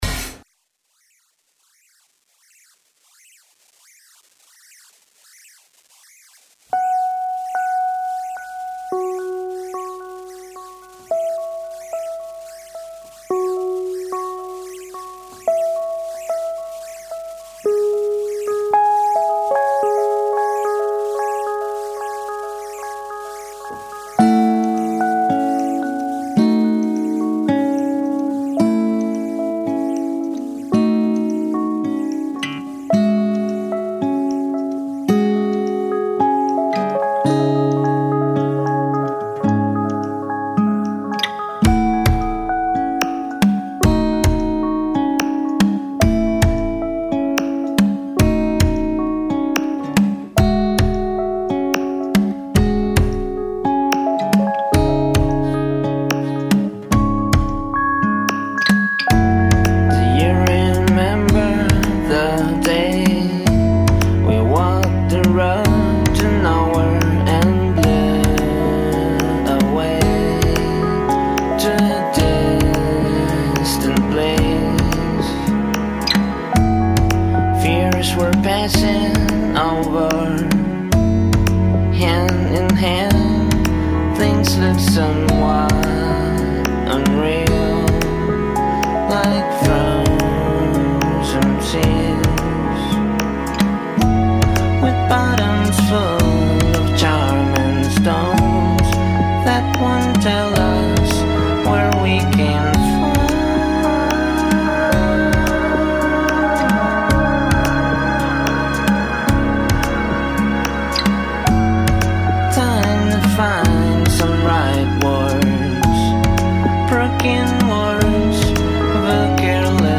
Un’ora di divagazione musicale